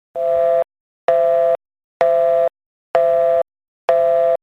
telephonebusy1.mp3